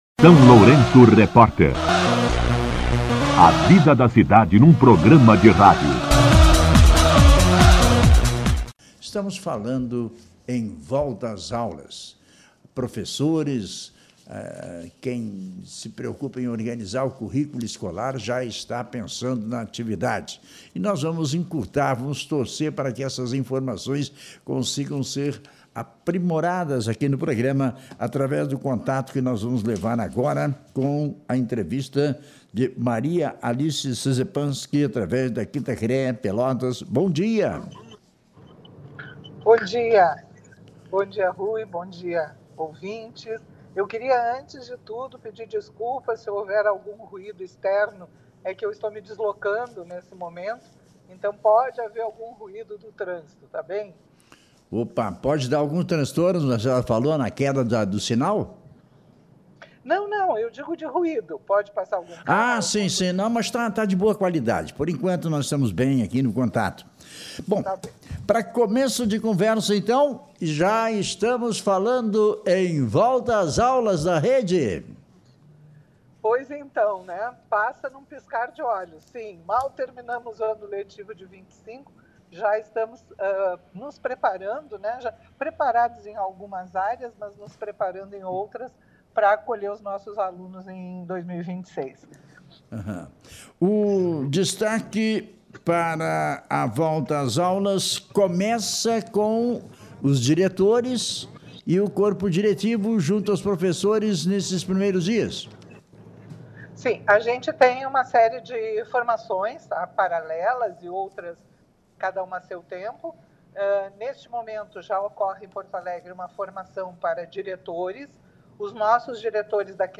Entrevista com a coordenadora regional de Educação, Alice Maria Souza Szezepanski
A coordenadora regional de Educação, Alice Maria Souza Szezepanski, concedeu entrevista ao SLR RÁDIO na manhã desta terça-feira (3), quando falou sobre o retorno às aulas da Rede Estadual de Ensino e o início do primeiro semestre letivo de 2026.